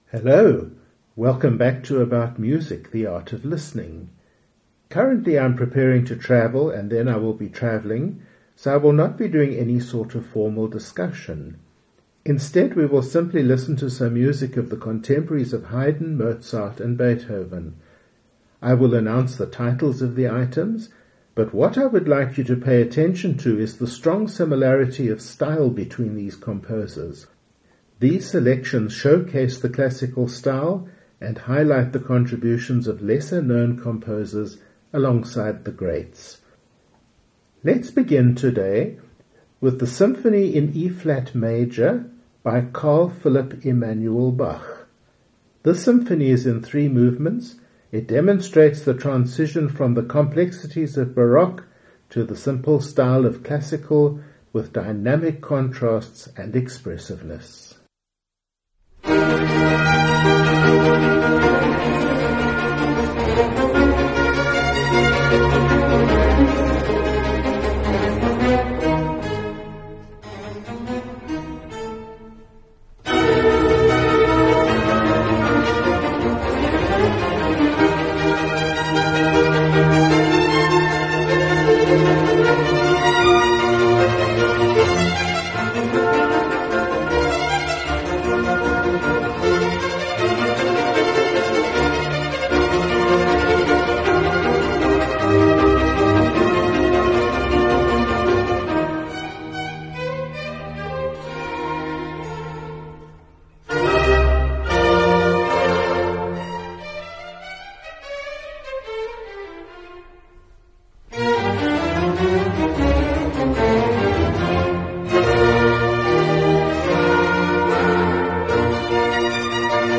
This is because all were writing during the 18th century in the prevalent style which was at first moving from Baroque into the polite Classical, especially Viennese Classical period.
These selections showcase the Classical style and highlight the contributions of lesser-known composers alongside these greats.
These two programmes balance instrumental variety and stylistic depth, featuring symphonies, concertos, chamber works, and solo repertoire from composers who shaped and enriched the Classical style.